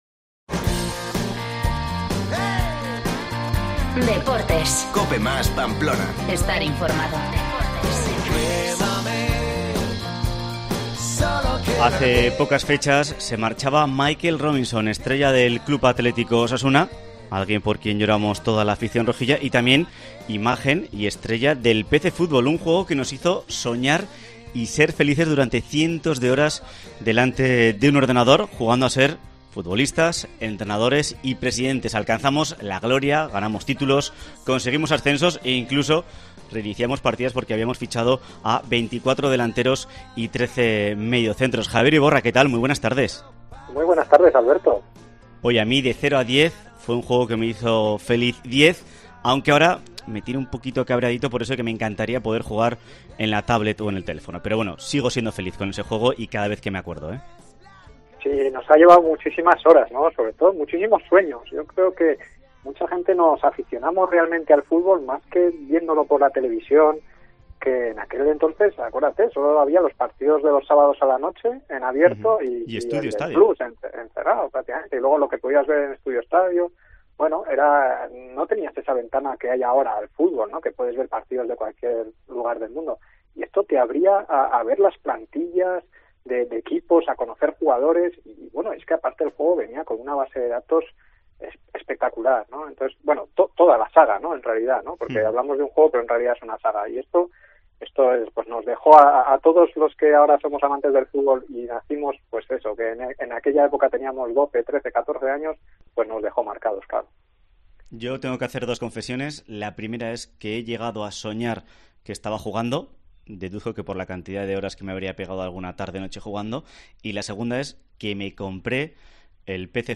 PC Fútbol, el juego que nos hizo soñar. Entrevistamos